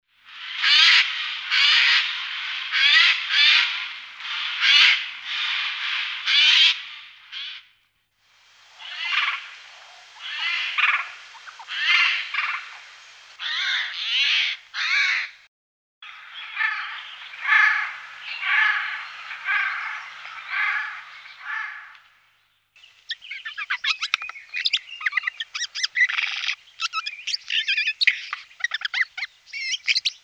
Orto botanico - Ghiandaia
Il maschio corteggia la femmina emettendo un canto rauco e sgraziato e offrendole cibo. La ghiandaia è in grado inoltre di imitare i suoni percepiti nell’ambiente circostante, dal canto degli uccelli alla voce umana.
ghiandaia.mp3